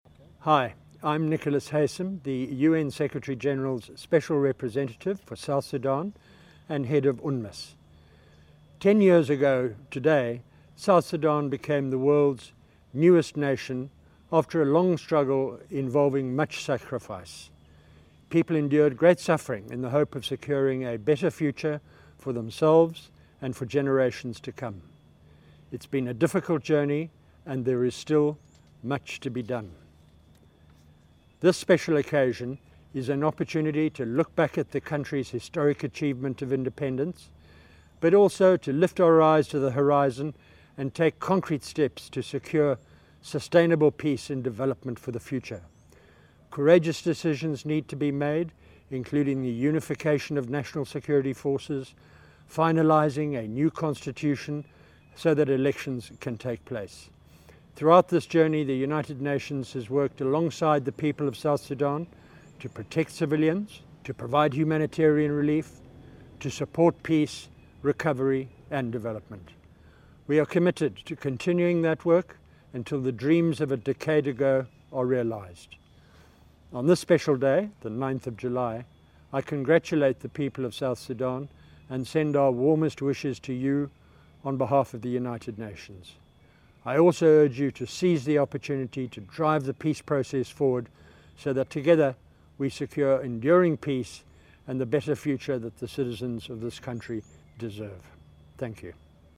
SRSG Nicholas Haysom's Independence Day Message
As the country commemorates 10years of its independence, UNMISS chief Nicholas Haysom has a special message for the citizens of the world’s youngest nation.